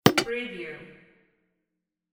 Button Rollover Sound Effect #22
Description: Button rollover sound effect for flash animations, websites, games, video productions, etc.
Keywords: button, rollover, roll, over, interface, flash, game, multimedia, animation, software, application, menu, navigation, click, alert, switch
button-preview-22.mp3